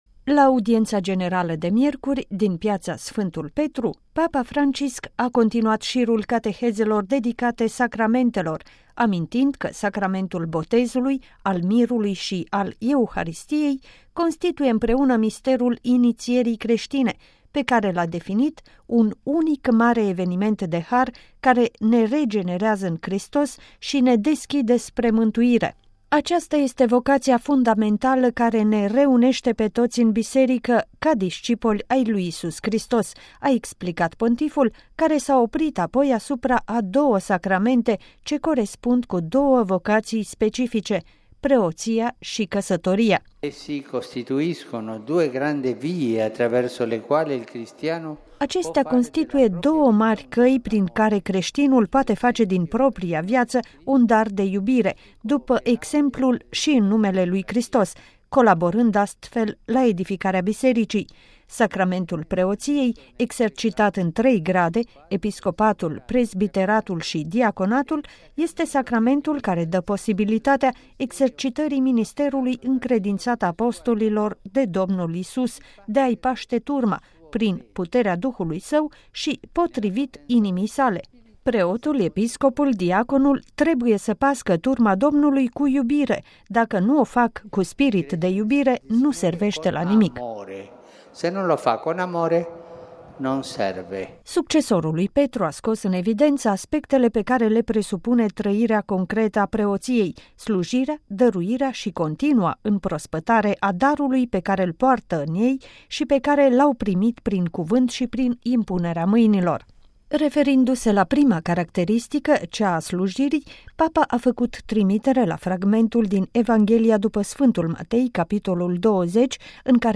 Sacramentul Preoţiei presupune slujire, dăruire şi continua împrospătare a darului primit: Papa Francisc, la audienţa generală
Primiţi acum binecuvântarea Sfântului Părinte de la finalul audienţei generale de miercuri, 26 martie, a cărei cateheză a fost dedicată Sacramentului Preoţiei.